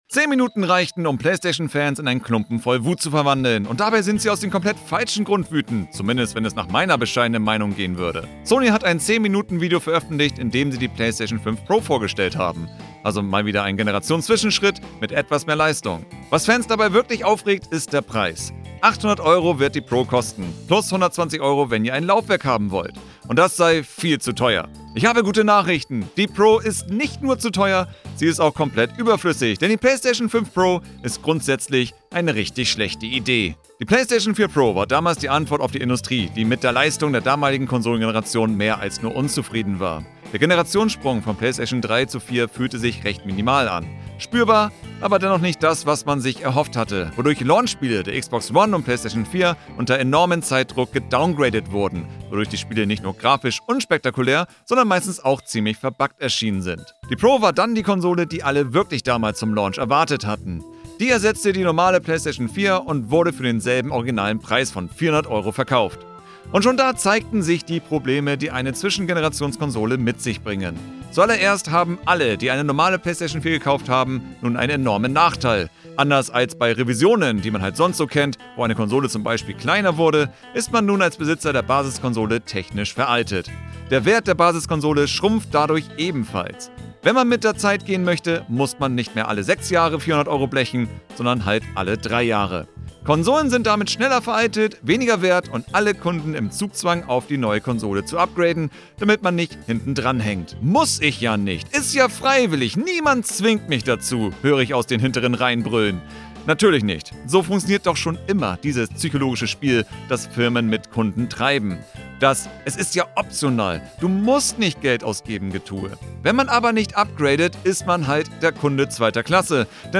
Update: Habe den Text neu aufgezeichnet, nun ohne Sound Probleme!